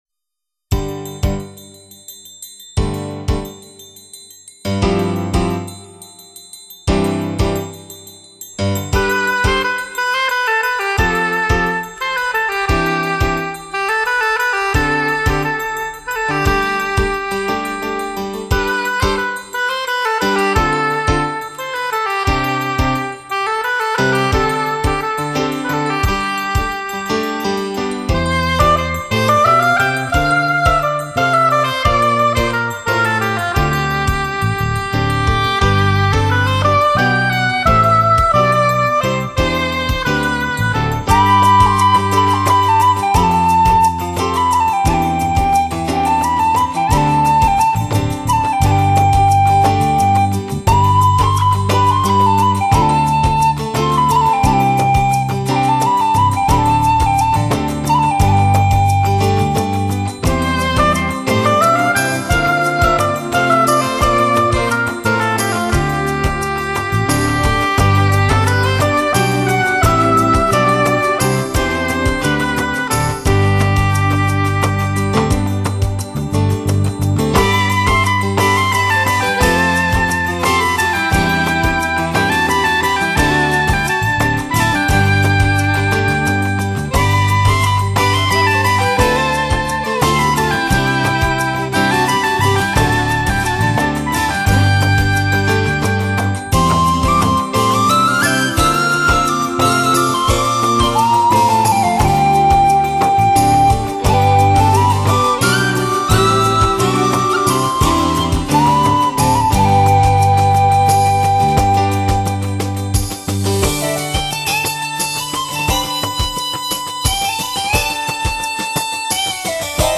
整张专辑中的乐曲内容超凡脱俗，展现了凯尔特人浪漫与坚强的独特气质。